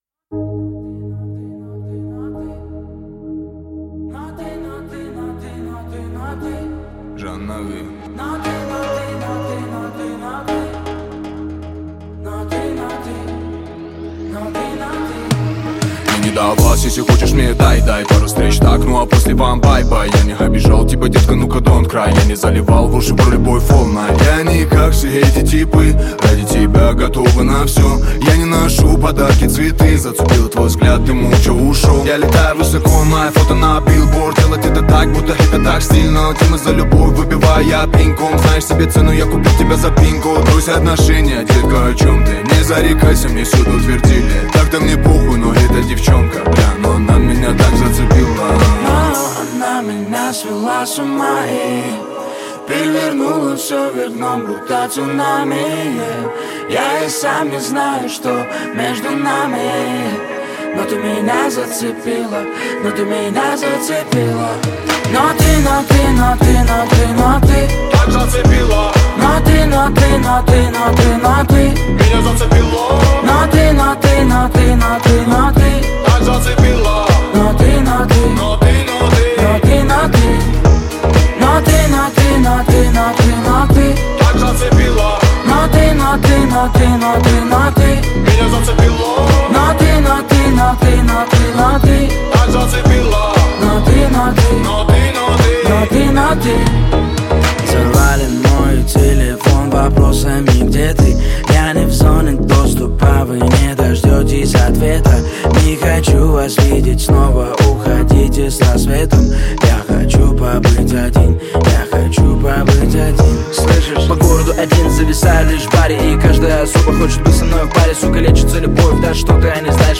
Хип-хоп
Жанр: Жанры / Хип-хоп